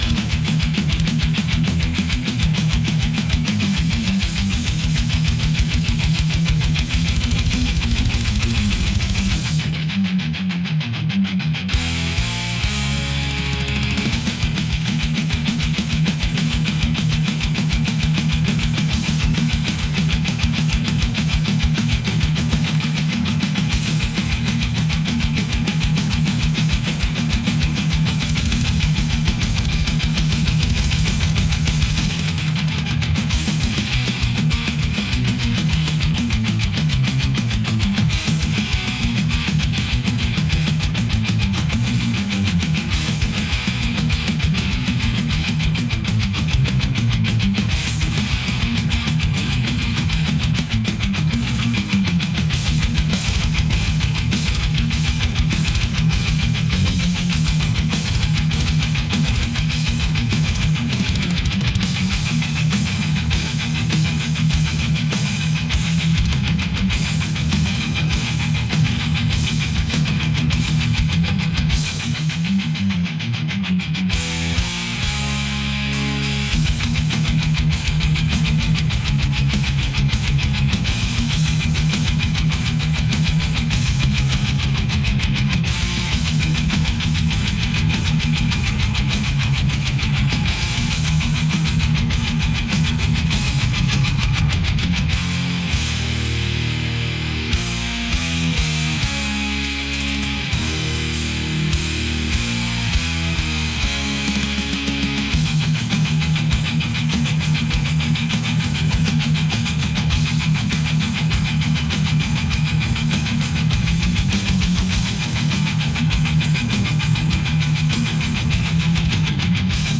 aggressive | intense | metal